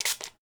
SPRAY_Manual_RR4_mono.wav